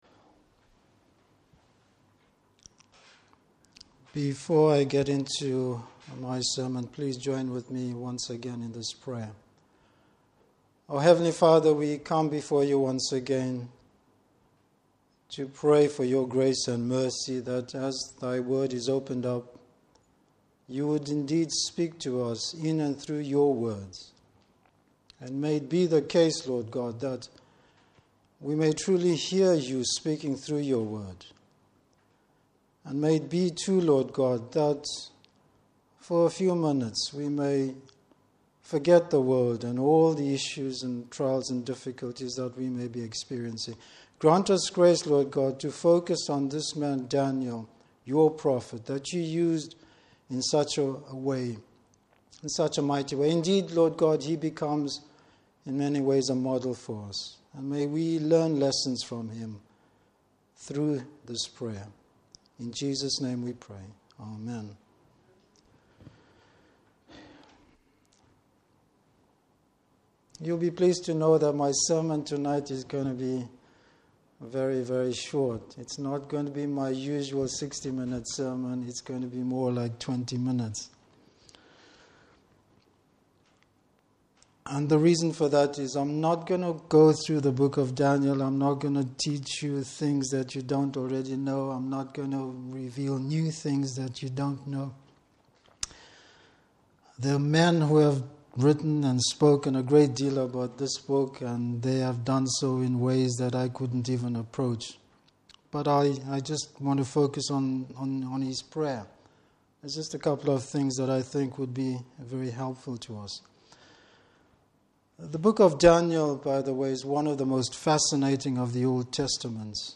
Service Type: Evening Service Bible Text: Daniel 9:1-19.